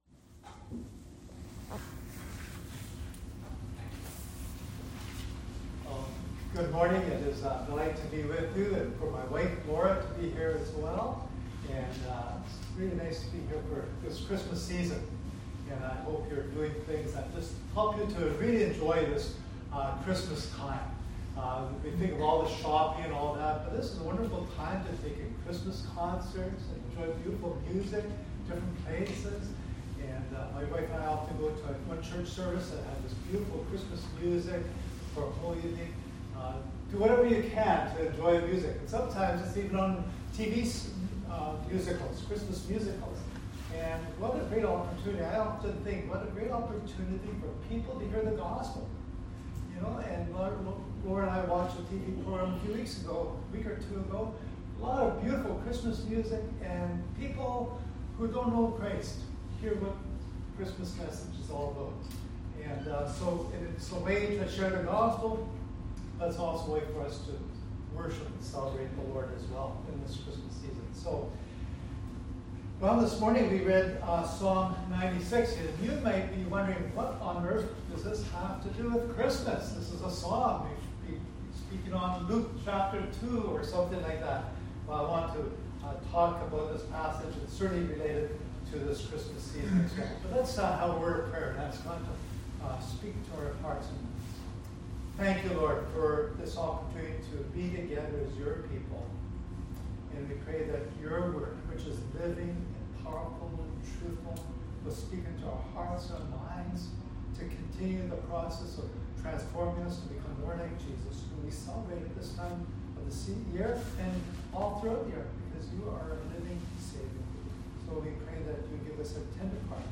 Passage: Psalm 96 Service Type: Sunday Worship